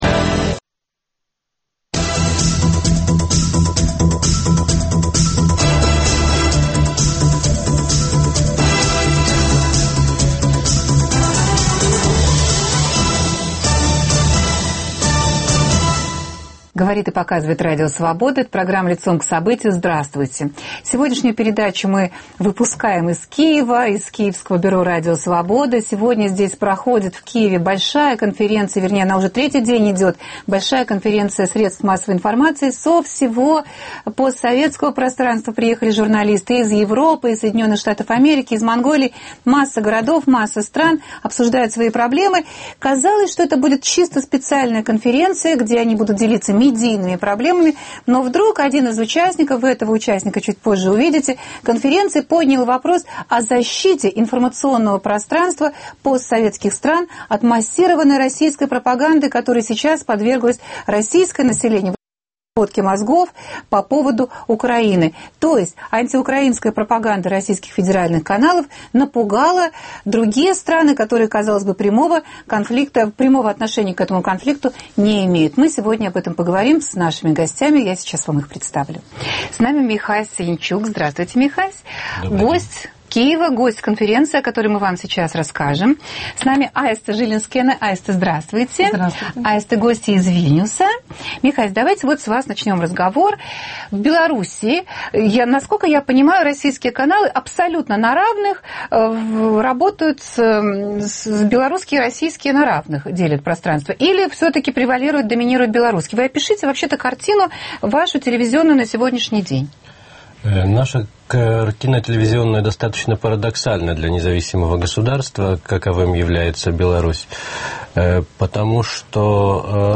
По окончании записи, сделанной в Киеве, разговор продолжится в московской студии Радио Свобода.